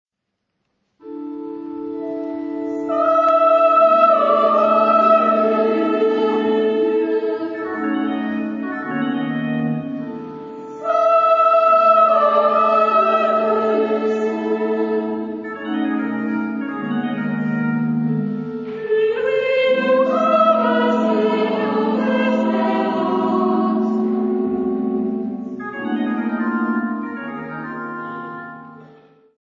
Genre-Style-Forme : Sacré ; Hymne (sacré)
Type de choeur :  (2 voix égales )
Instrumentation : Orgue